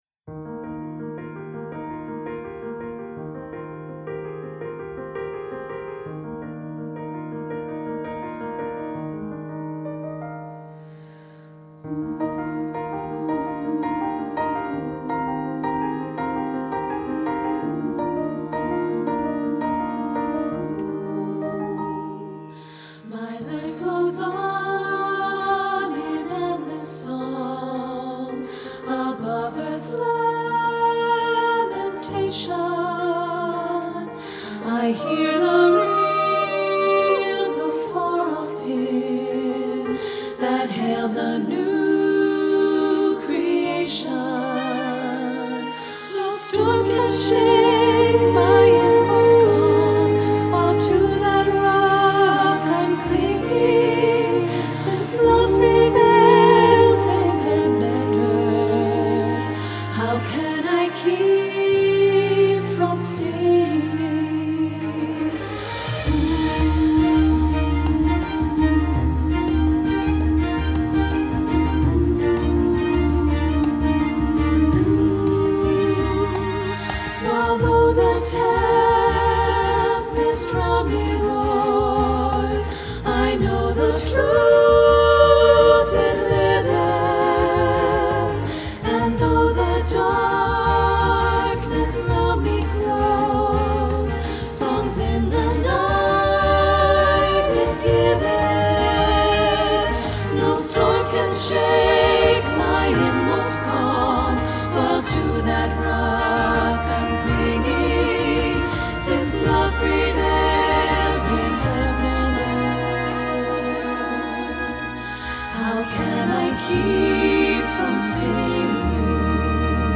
Choral Folk Women's Chorus
SSAA
SSAA Audio